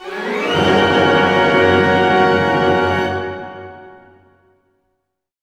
Index of /90_sSampleCDs/Roland - String Master Series/ORC_Orch Gliss/ORC_Major Gliss